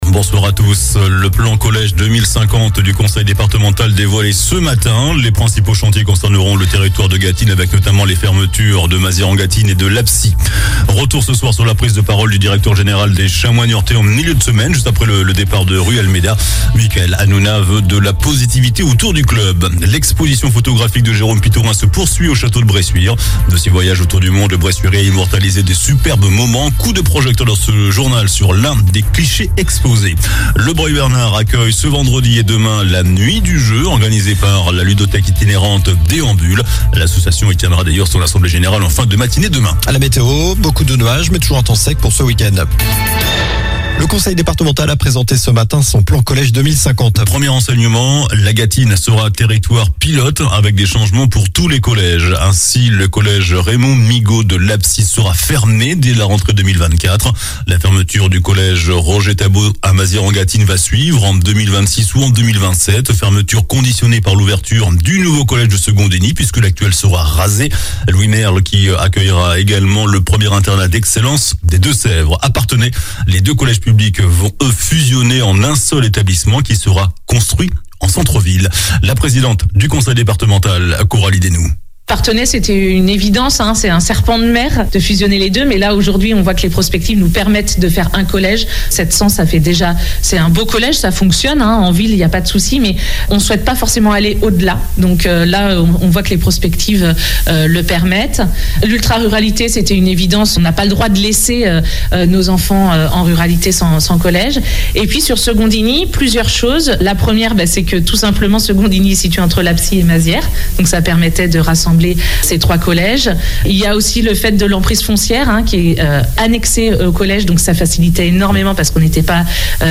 JOURNAL DU VENDREDI 03 FEVRIER ( SOIR )